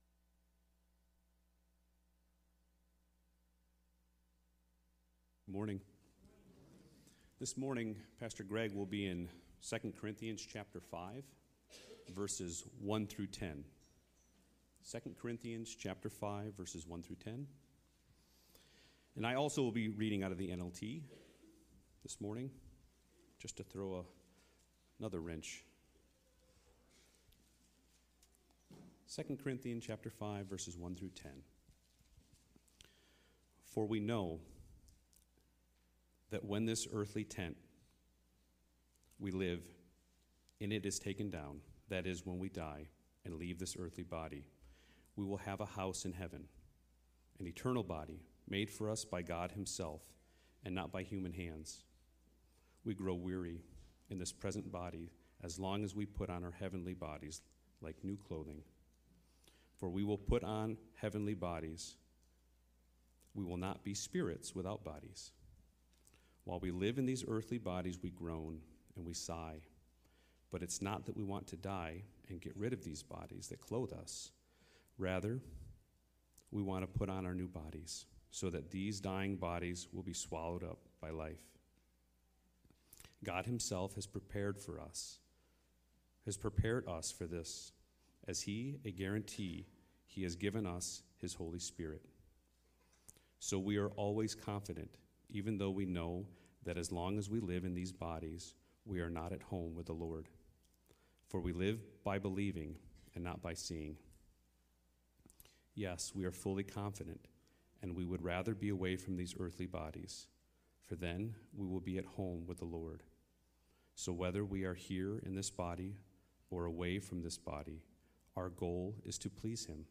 Sermons | Calvary Baptist Church
Single sermons that are not part of a series.